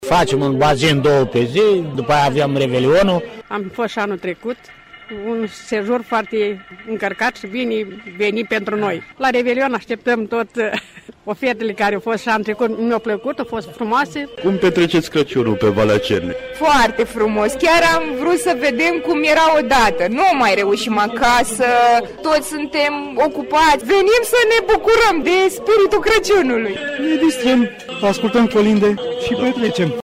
a stat de vorbă cu oaspeţii veniţi în Valea Cernei